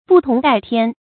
不同戴天 注音： ㄅㄨˋ ㄊㄨㄙˊ ㄉㄞˋ ㄊㄧㄢ 讀音讀法： 意思解釋： 戴：加在頭上或用頭頂著。